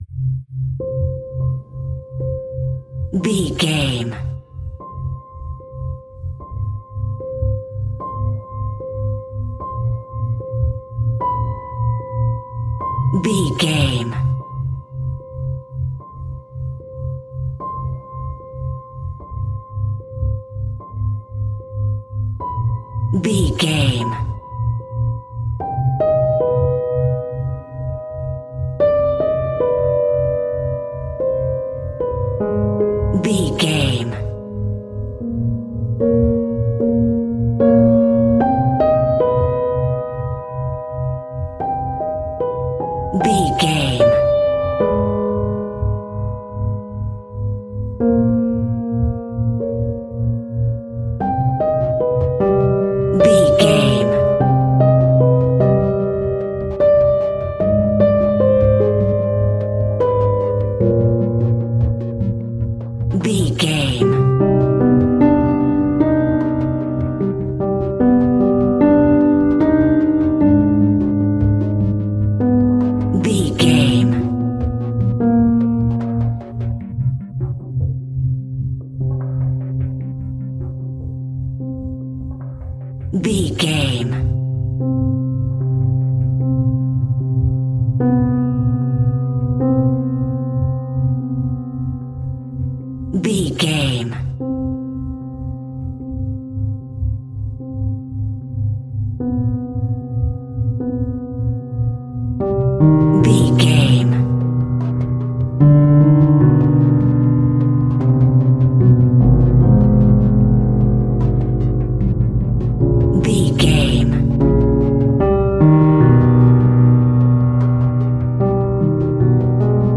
Other World Horror Piano.
Aeolian/Minor
DOES THIS CLIP CONTAINS LYRICS OR HUMAN VOICE?
dark
haunting
eerie
electric piano
synthesiser
horror music